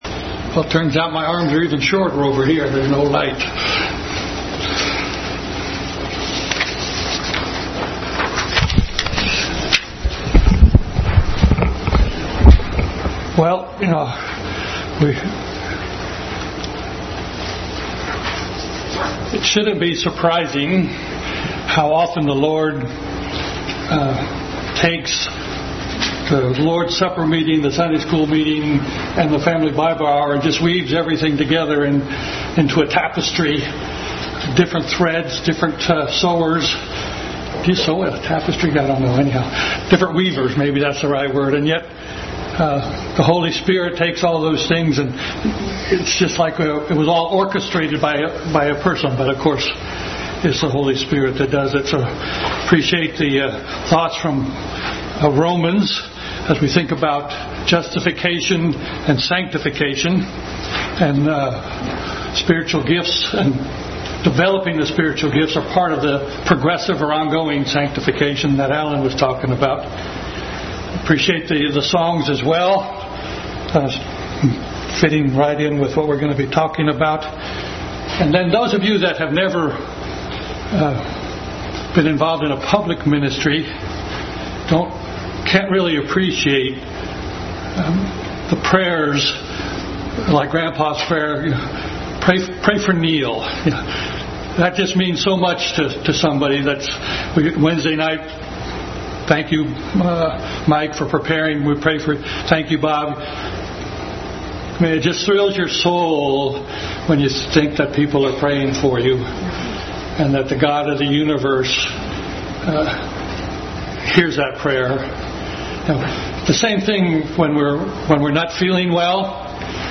Bible Text: 1 Corinthians 12, Romans 12, Ephesians 4, 1 Peter 4 | Family Bible Hour continued study on spiritual gifts.